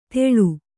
♪ teḷu